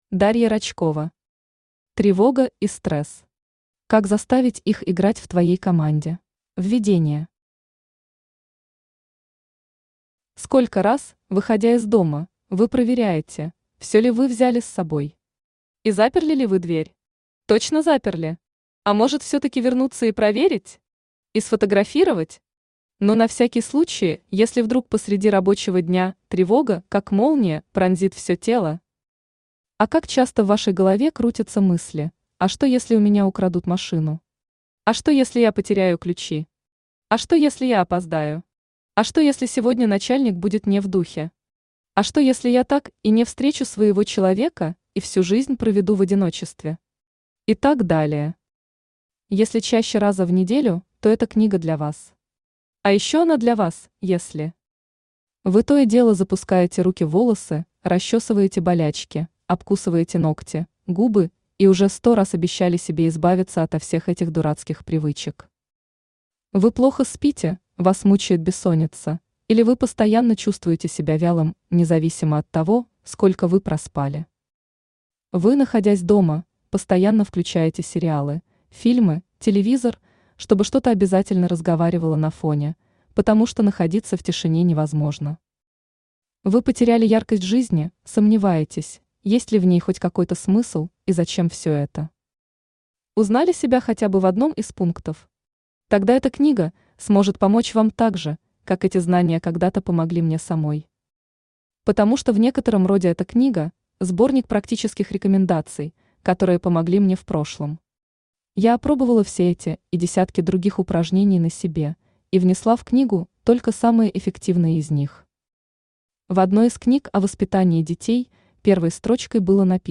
Аудиокнига Тревога и стресс. Как заставить их играть в твоей команде | Библиотека аудиокниг
Читает аудиокнигу Авточтец ЛитРес.